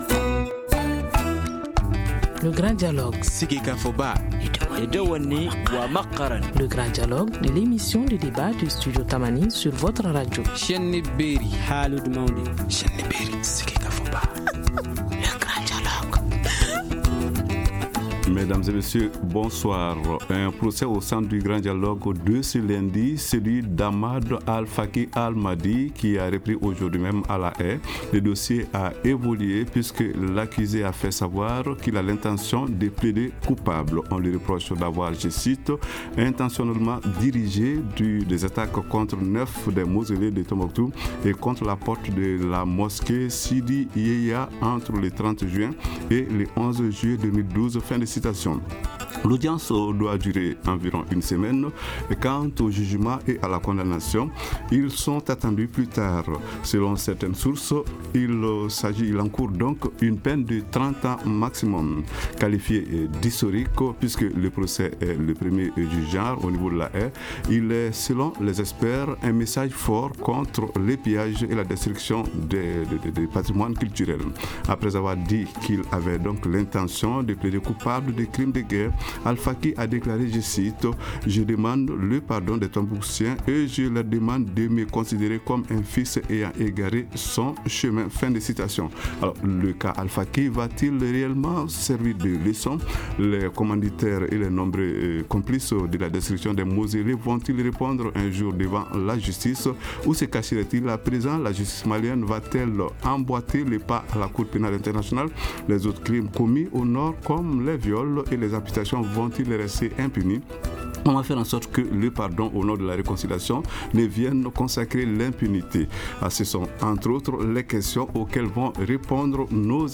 A ces questions et à bien d’autres vont répondre nos invités.